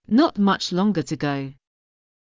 ｲｯﾄ ｳｨﾙ ｽｰﾝ ﾋﾞｰ ﾌｨﾆｯｼｭﾄﾞ